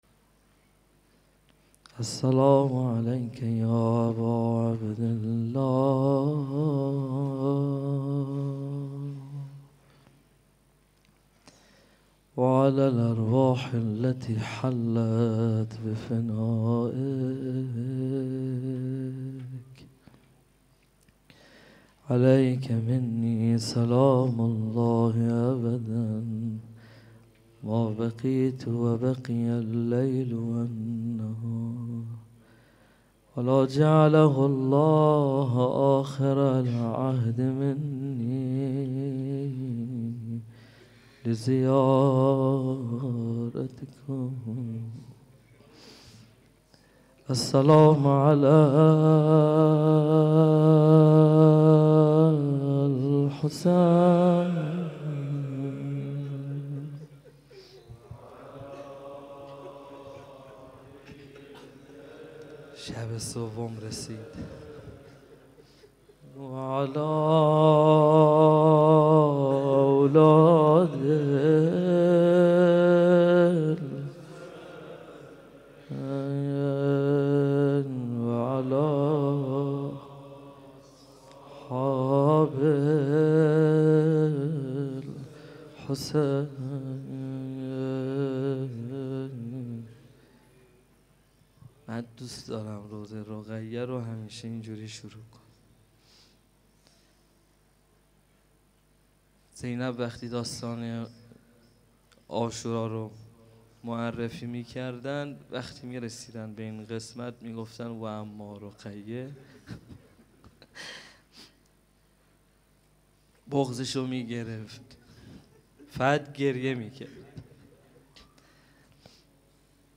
روضه خوانی
مراسم عزاداری شب سوم محرم 1441 هجری قمری